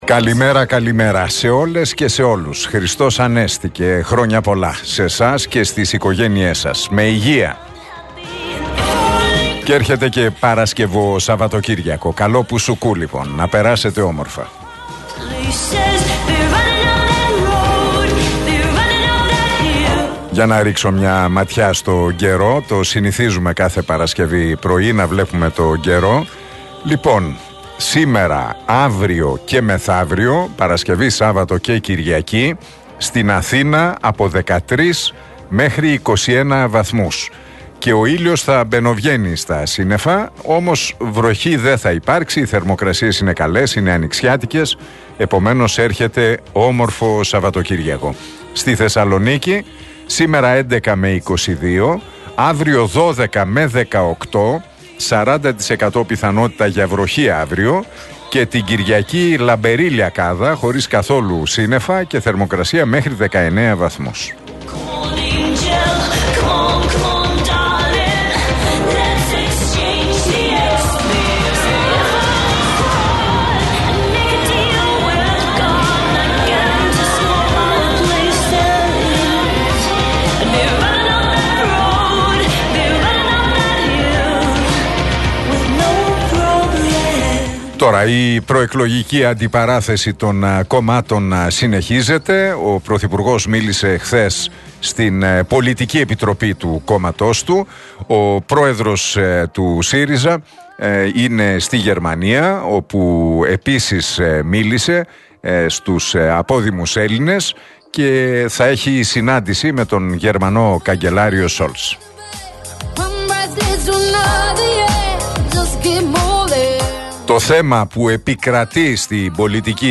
Ακούστε το σχόλιο του Νίκου Χατζηνικολάου στον RealFm 97,8, την Παρασκευή 21 Απριλίου 2023.